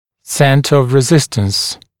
[‘sentə əv rɪ’zɪstəns][‘сэнтэ ов ри’зистэнс]центр резистентности, центр сопротивления